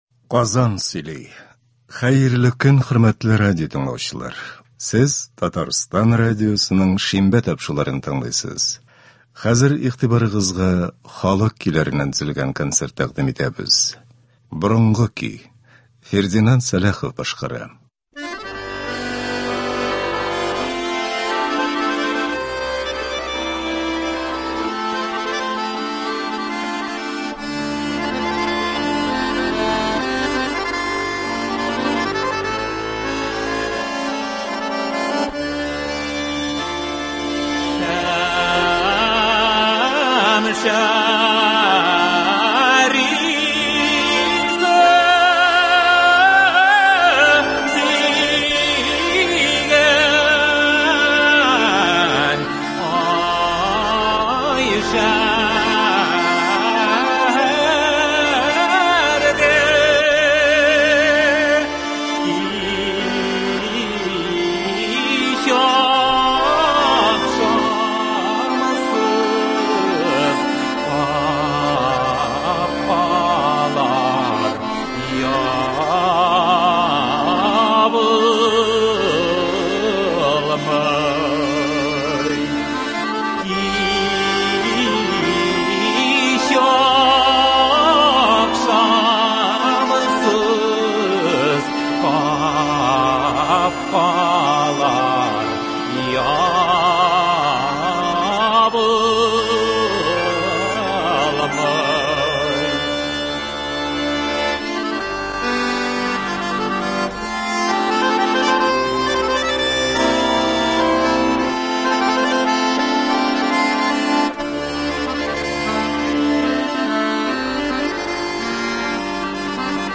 Татар халык җырлары (30.04.22)
Бүген без сезнең игътибарга радио фондында сакланган җырлардан төзелгән концерт тыңларга тәкъдим итәбез.